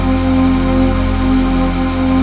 DM PAD2-83.wav